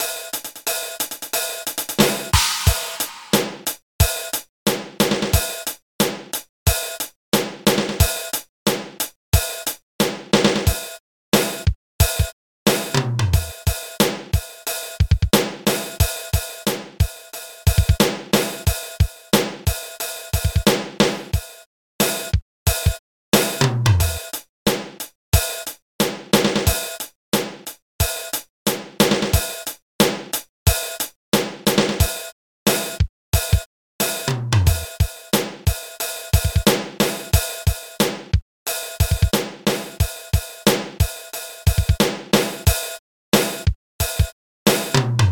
Mein Hydrogen Drumsong als Hörbeispiel
Für mich ist das nicht der Top Sound. Das klingt alles sehr nach Casio CTK Keyboard.